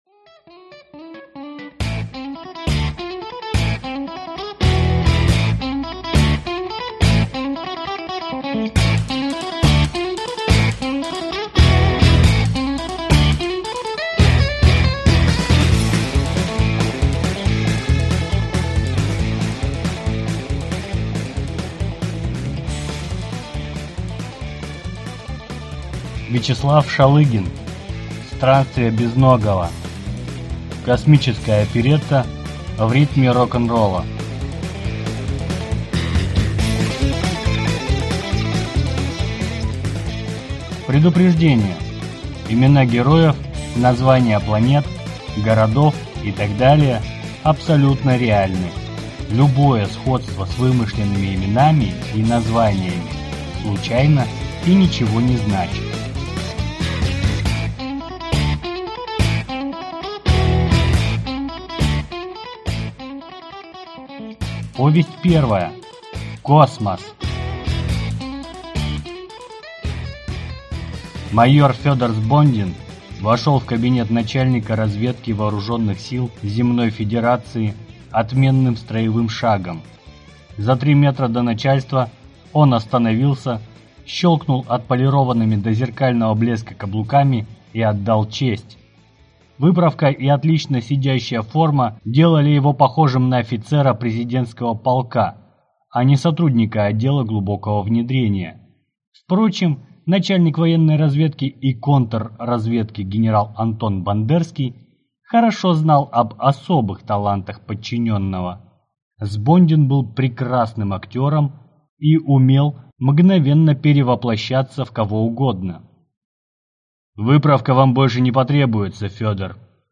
Aудиокнига Космос!